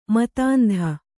♪ matāndha